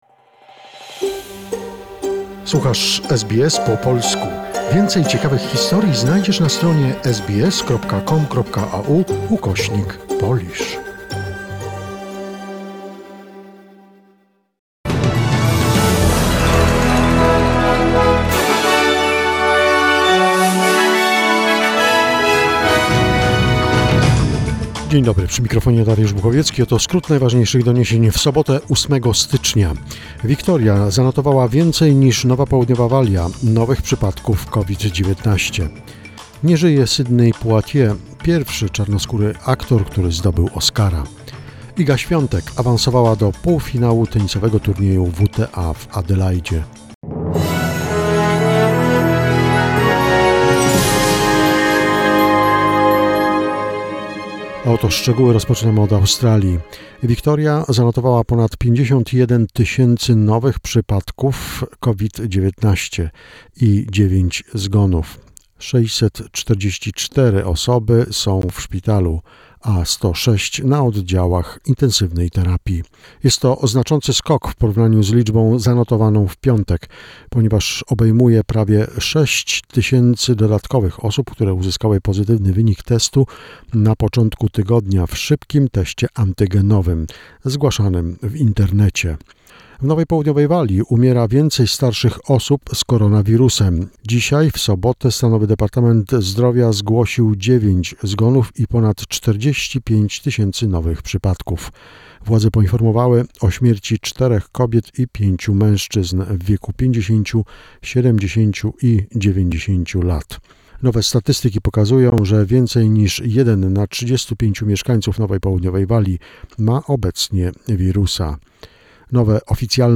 SBS News Flash in Polish, 8 January 2021